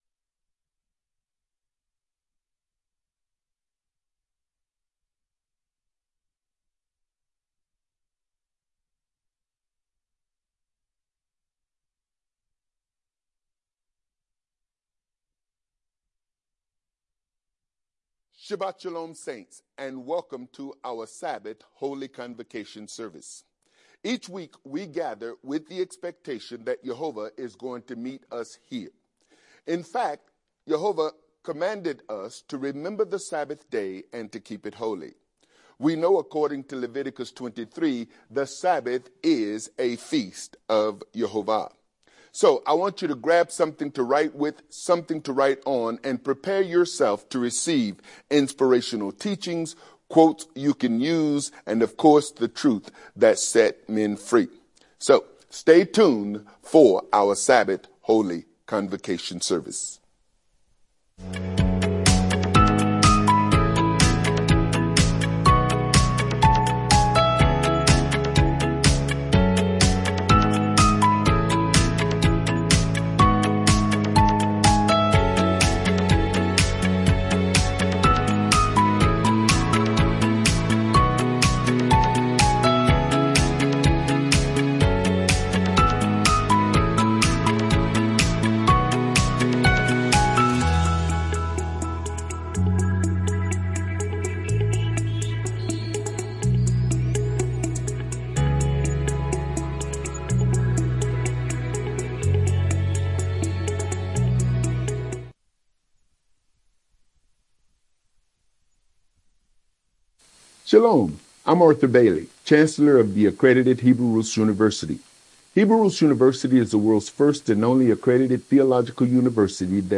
Sabbath-Service-Nothing-But-The-Blood_01.mp3